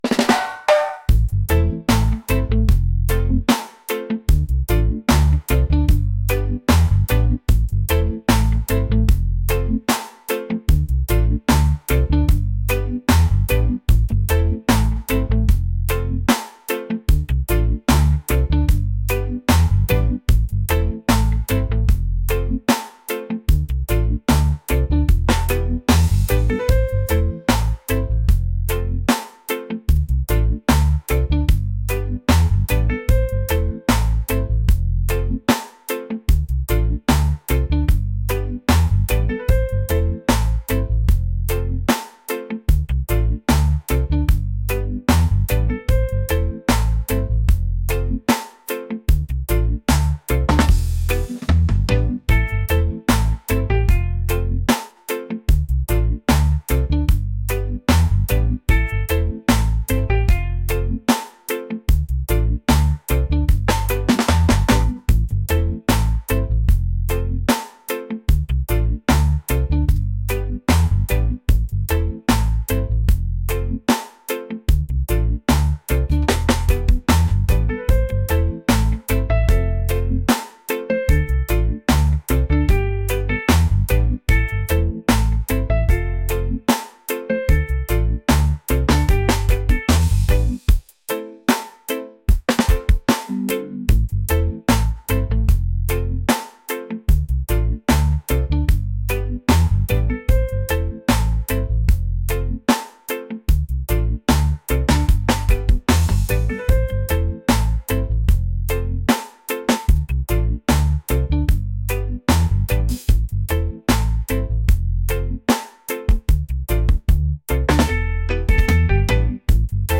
relaxed | reggae | laid-back